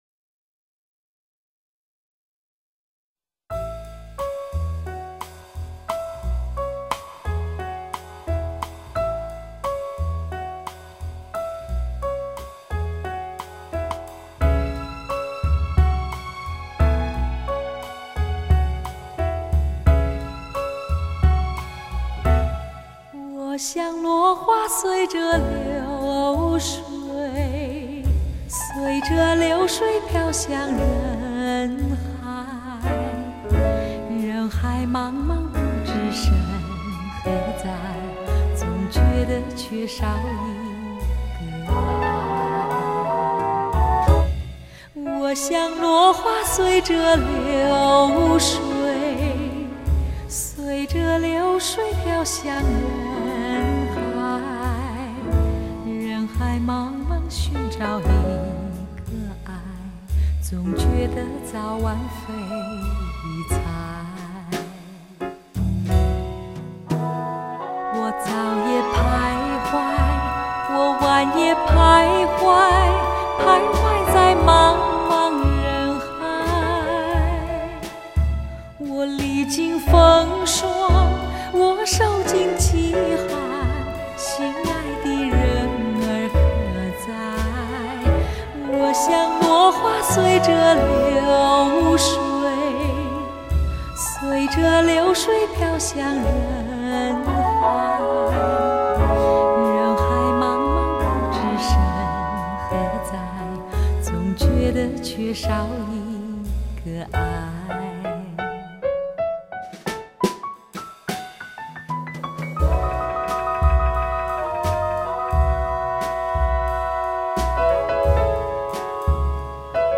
音乐类型: 试音碟